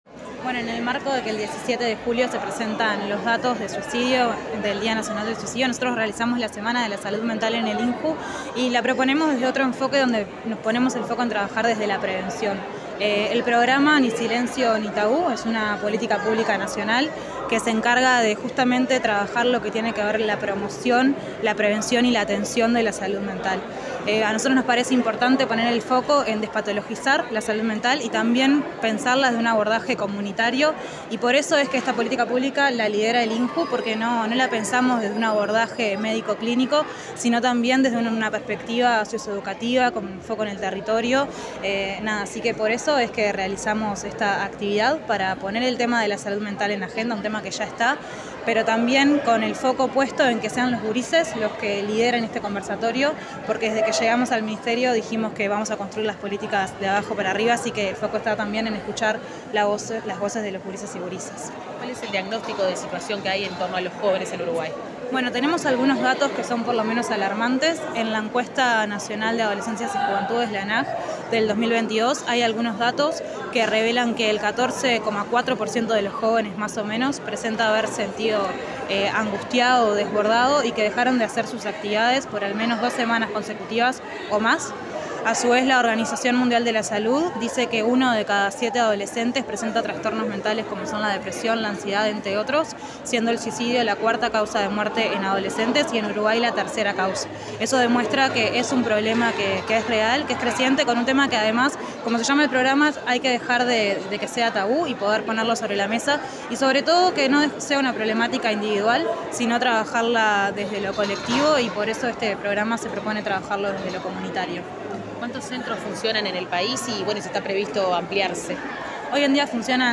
Autoridades del Ministerio de Desarrollo Social estuvieron presentes en la actividad celebrada en la Casa INJU bajo el nombre “En voz alta: juventudes y bienestar emocional” con el objetivo de visibilizar, reflexionar y dialogar sobre el bienestar emocional de adolescentes y jóvenes.
Audio del ministro de Desarrollo Social, Gonzalo Civila, y la directora del Instituto Nacional de la Juventud, Eugenia Godoy.